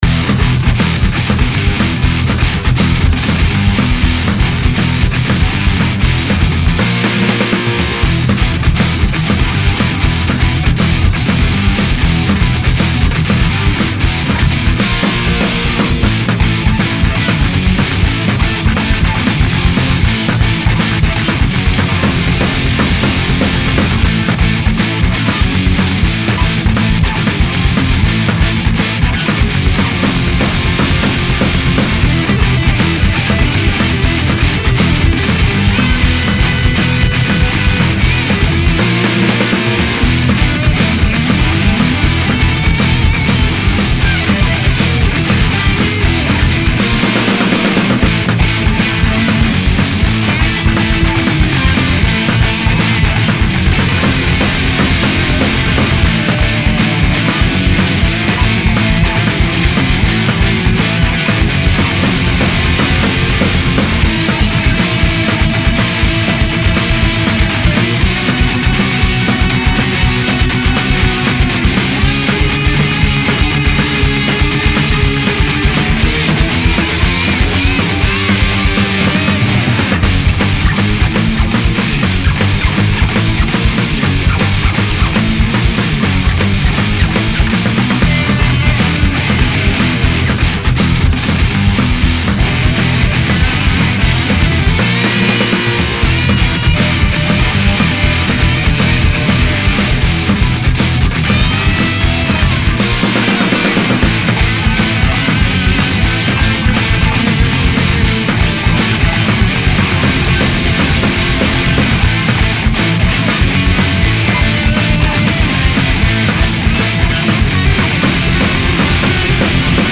action guitars
General Pop Tracks, Guitar pop tracks